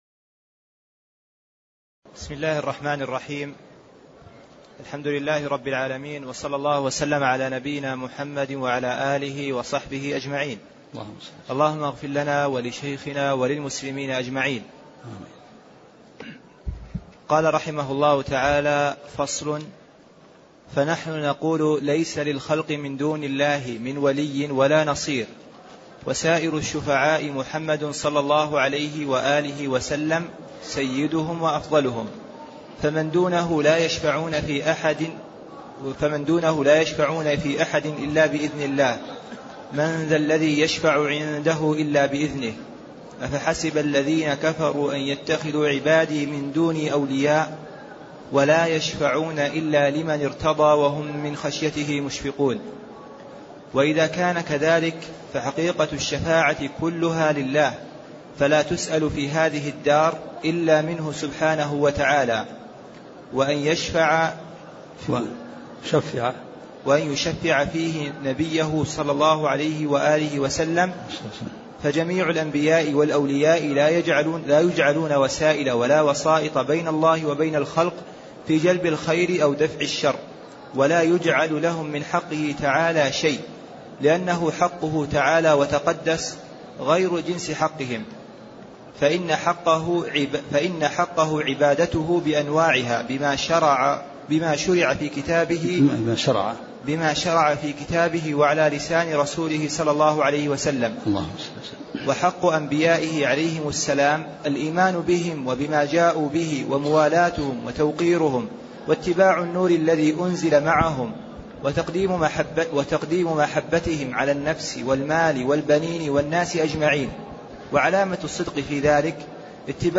تاريخ النشر ٦ شعبان ١٤٣٤ هـ المكان: المسجد النبوي الشيخ